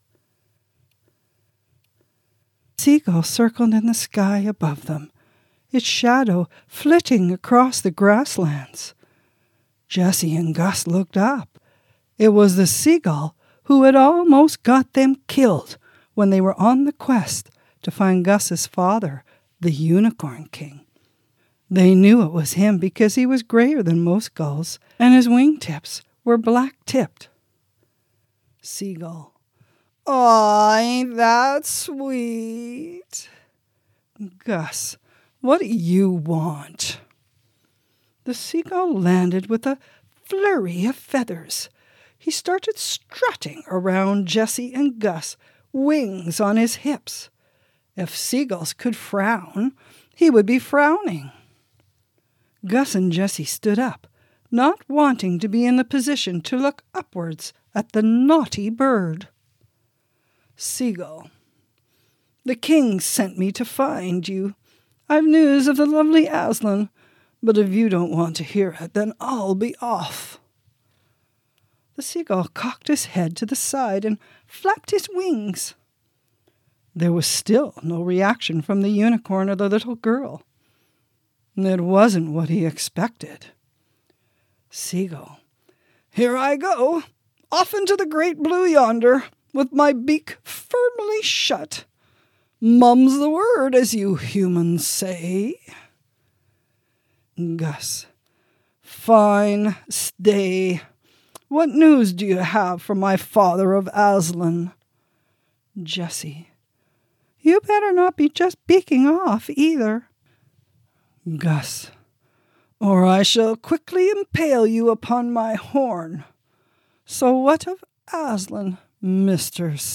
This is what started me reading these stories in the play format so that the younger and older children as well as those with learning disabilities could follow along.
The Unicorn Wears Red audio book will be released February 1st, just in time for you and the kids to enjoy a Valentine’s Day adventure as Gus and Jessie join forces with the naughty seagull to try to save Gus’s lost love, Aslin, from the evil sorceress, Morgana, in this fast paced bedtime short story complete with sound effects.